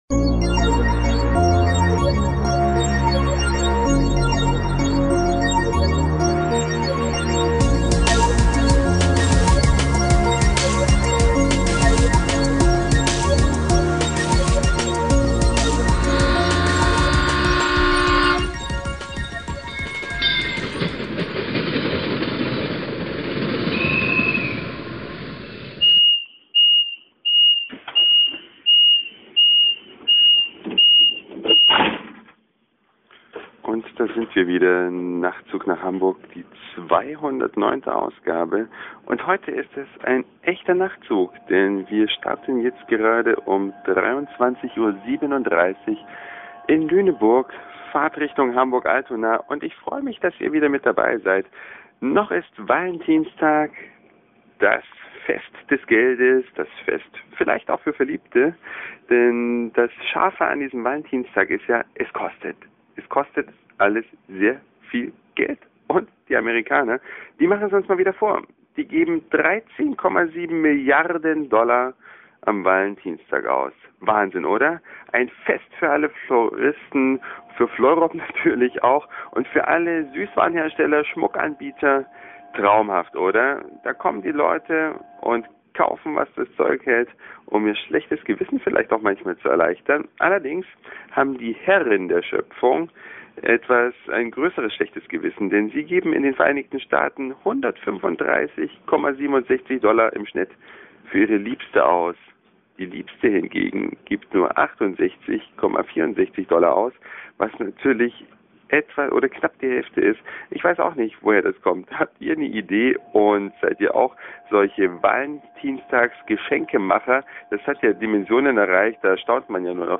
Dieser Podcast wurde live im Nachtzug nach Hamburg produziert.